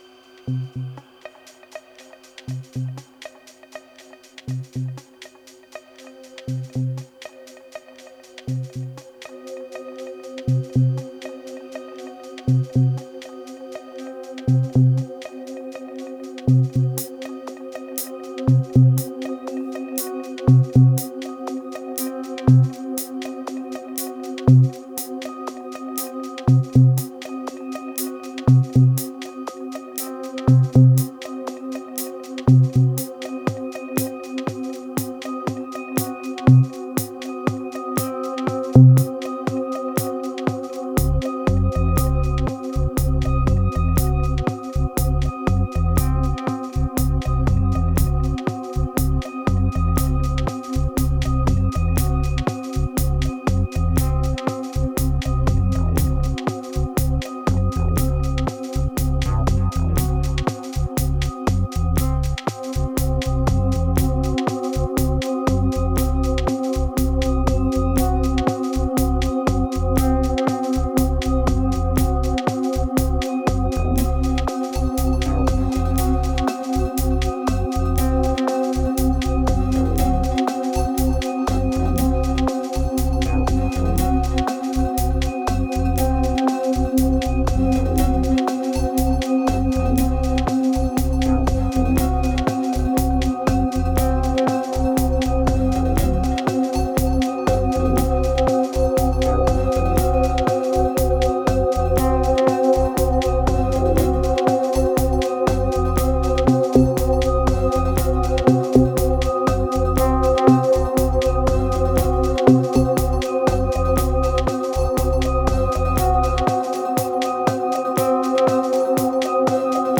2285📈 - 62%🤔 - 120BPM🔊 - 2017-01-07📅 - 368🌟